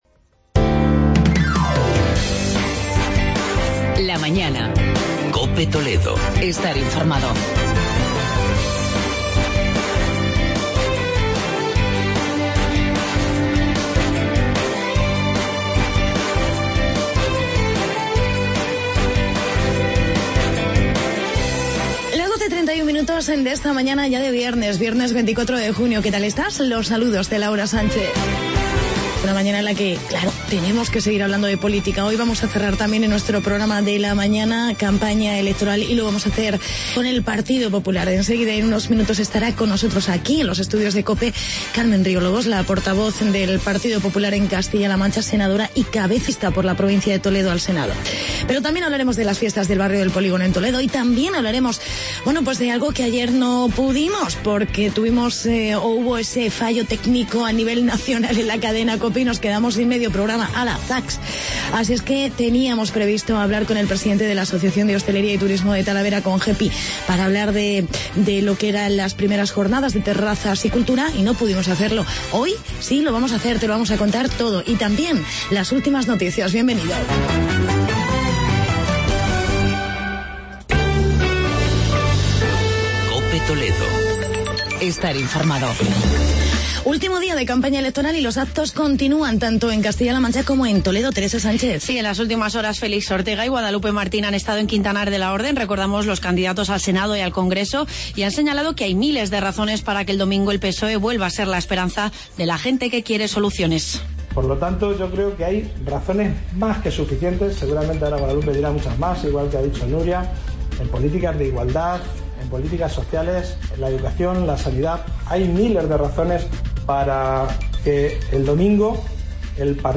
Entrevista con Carmen Riolobos, candidata popular al Senado y Reportajes sobre las Fiestas del Barrio del Polígono y la I Ruta "Terrazas y Cultura" de Talavera de la Reina.